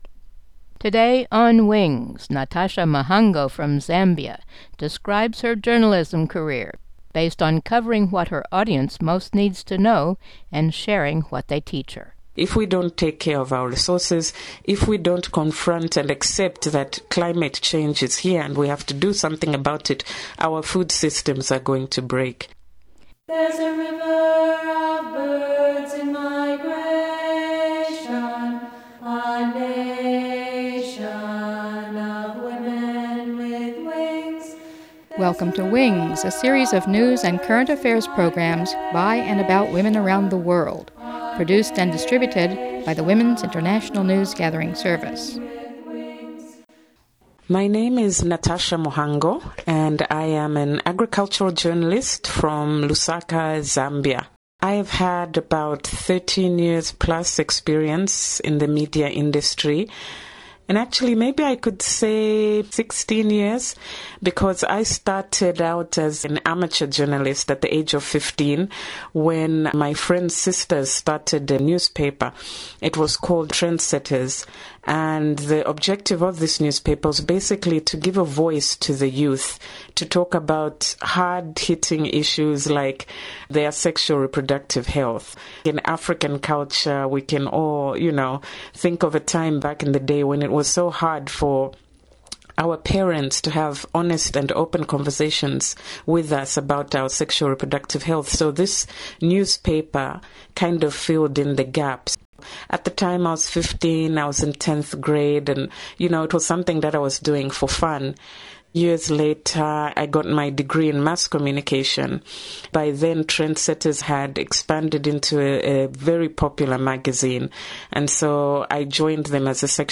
Mono
interviewed